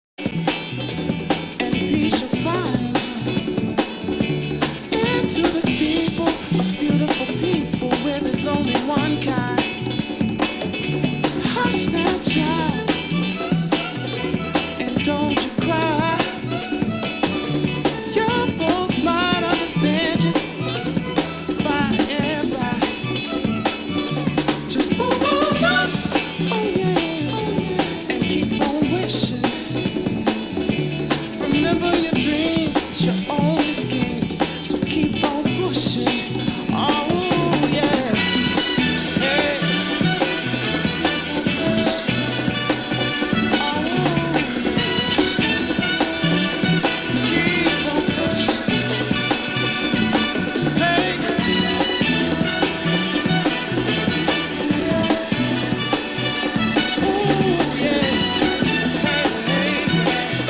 jazz-funk
drums